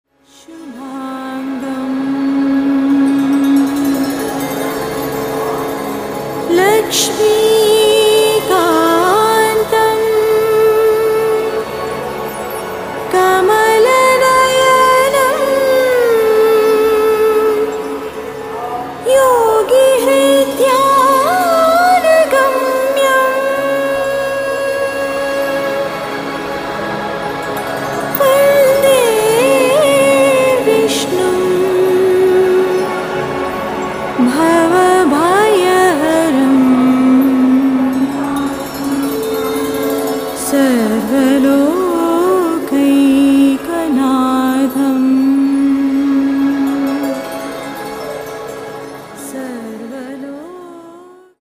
for that usual spaciously clear production